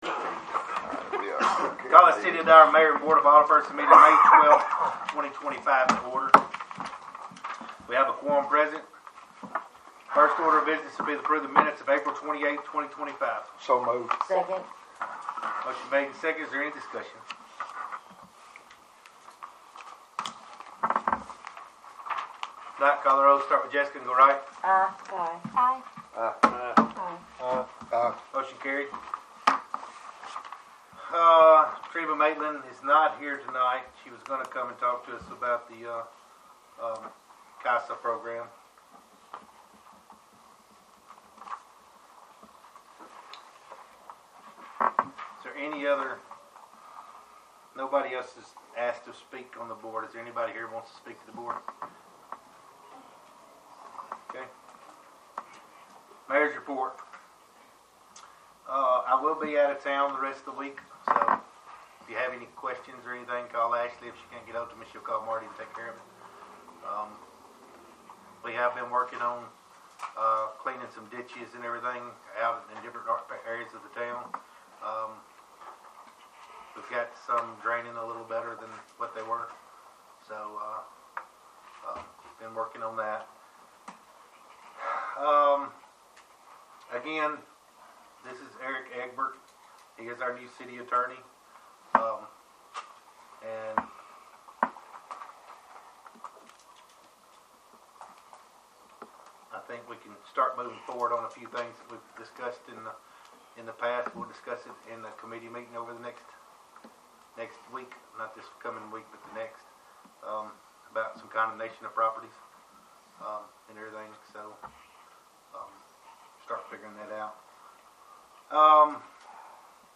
5-12-25 Regular Meeting